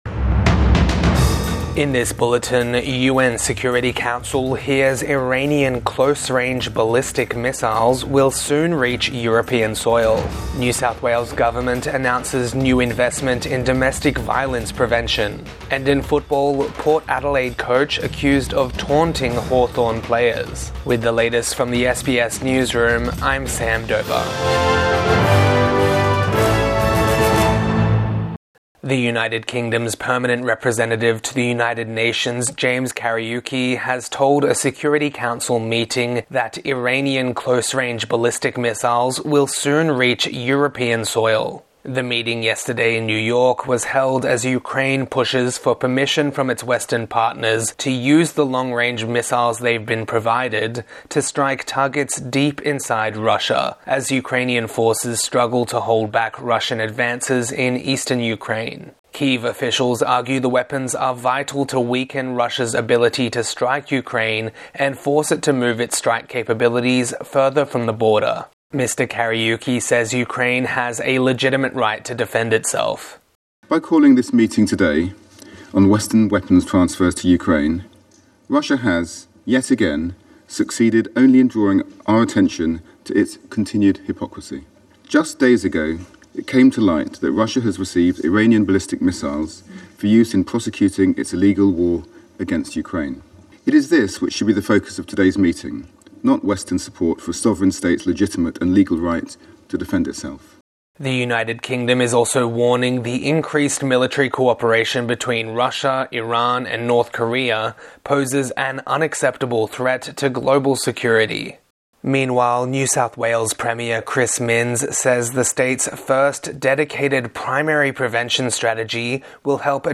Morning News Bulletin 14 September 2024